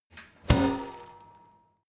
Royalty free sounds: Garbage can